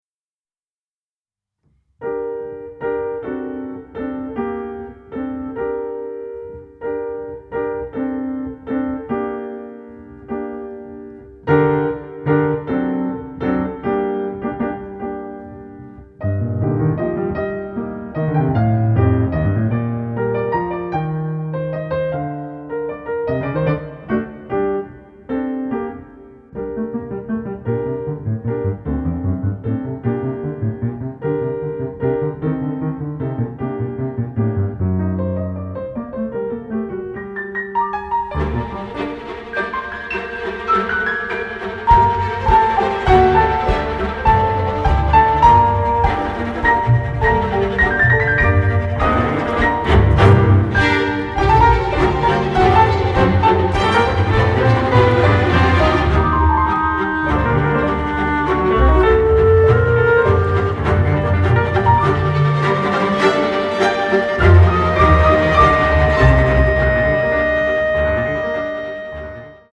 Recorded at Air Studios Lyndhurst Hall in London
piano